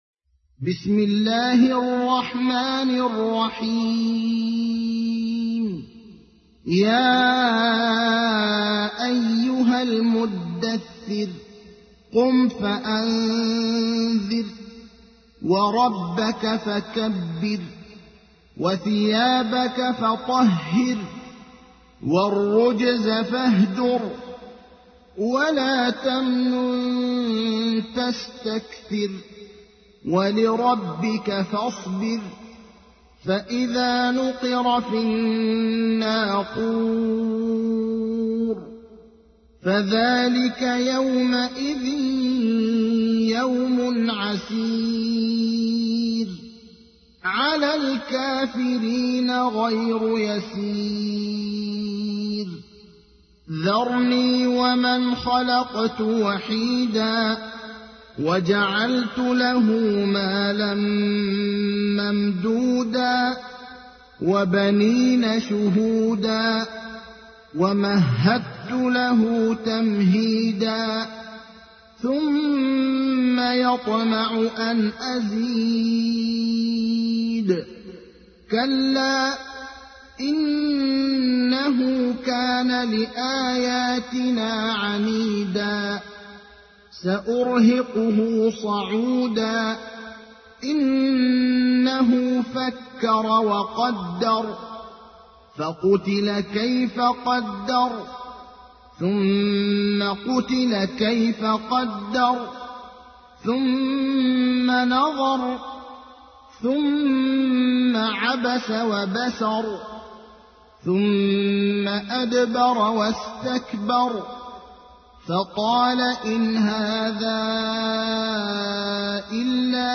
تحميل : 74. سورة المدثر / القارئ ابراهيم الأخضر / القرآن الكريم / موقع يا حسين